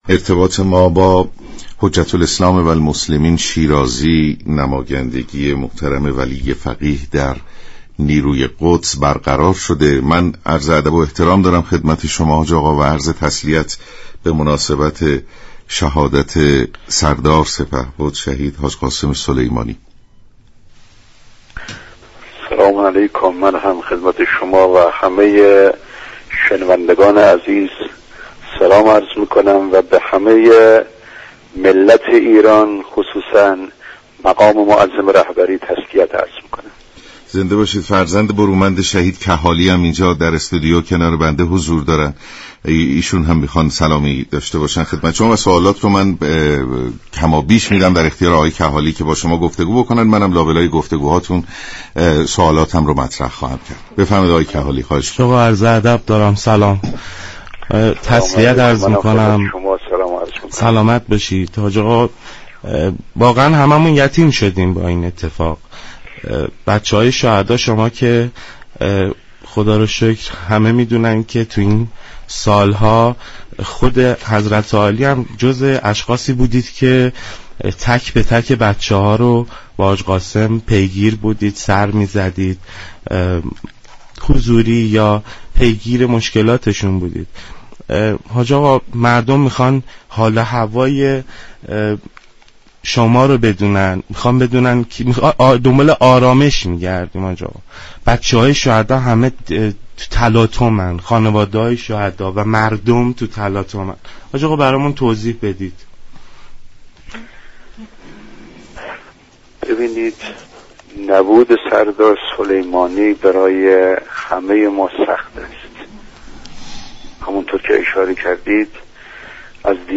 حجت‌الاسلام والمسلمین شیرازی نماینده ولی فقیه در نیروی قدس سپاه گفت: حضور گسترده مردم در صحنه نشان از ارتباط عمیق مقام معظم رهبری و فرماندهان سپاه با مردم دارد.